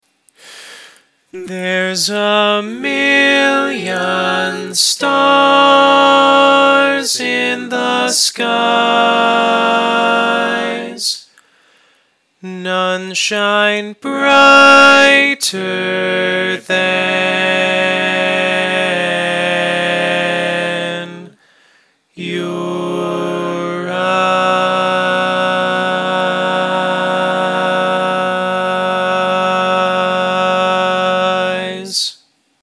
Key written in: G♭ Major
Type: Barbershop